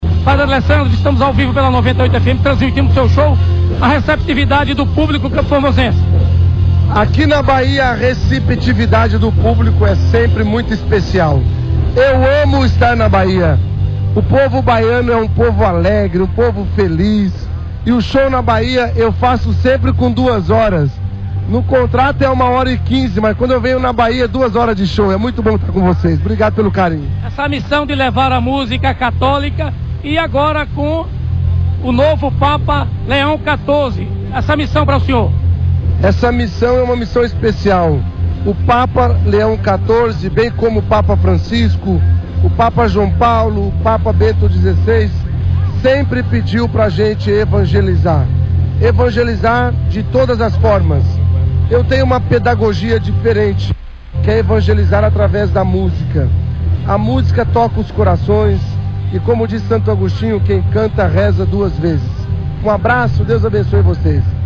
1º dia dia Arraiá da Freguesia